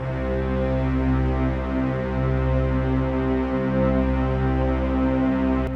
c4.wav